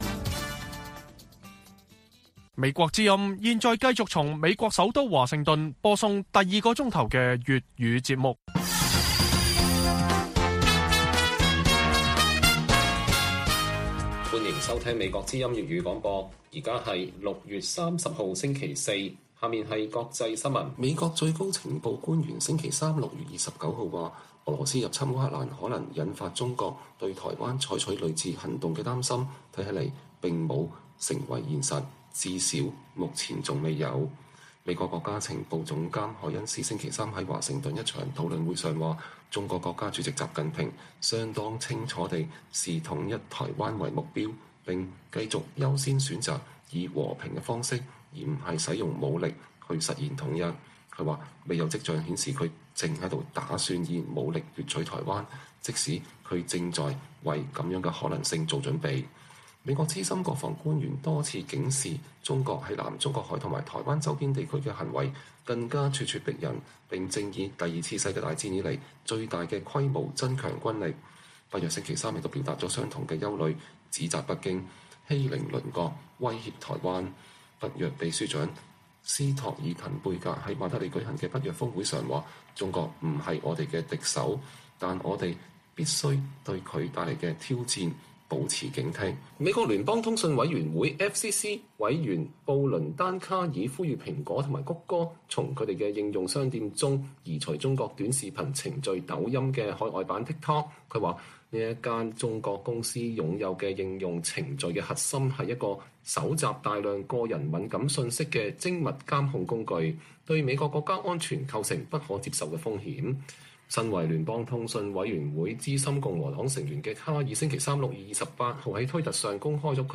粵語新聞 晚上10-11點: 香港獨立書店每月主題關注社運歷史大事，在國安法禁書潮下繼續發光